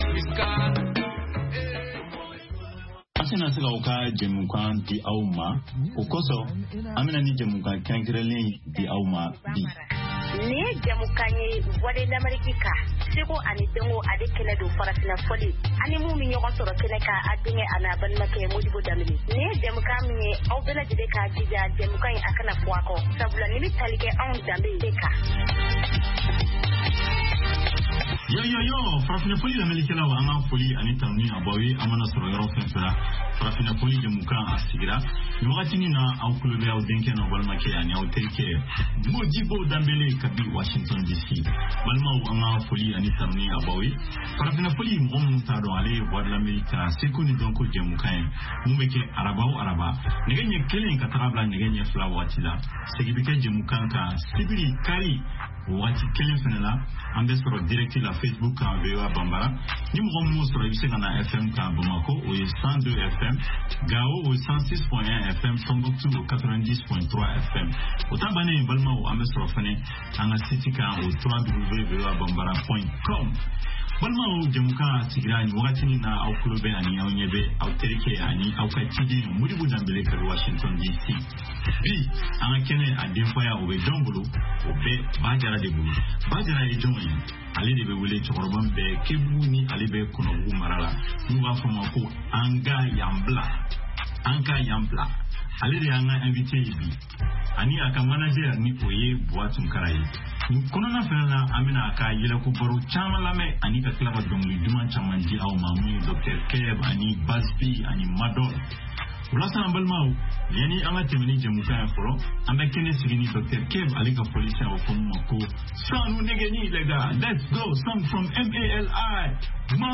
Bulletin d’information de 17 heures